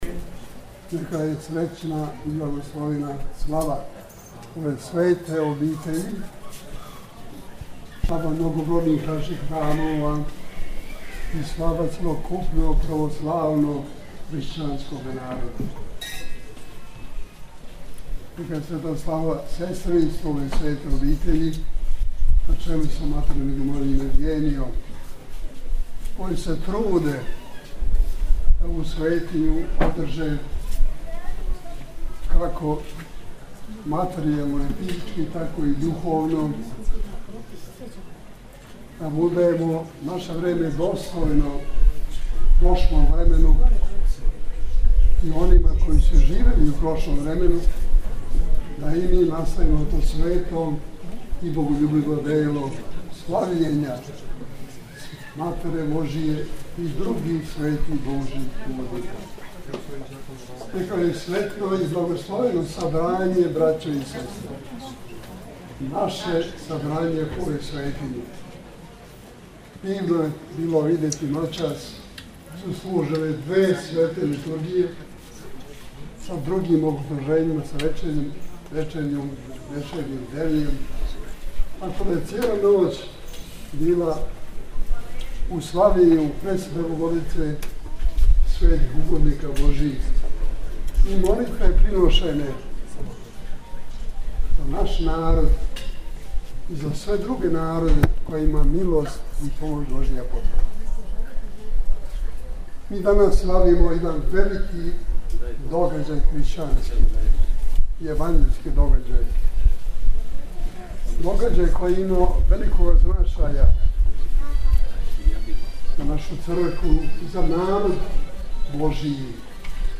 На празник Успења Пресвете Богородице Његова Светост Патријарх српски г. Иринеј служио је Свету архијерејску Литургију на отвореном у летњиковцу Манастира Раковица.